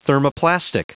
Prononciation du mot thermoplastic en anglais (fichier audio)
Prononciation du mot : thermoplastic
thermoplastic.wav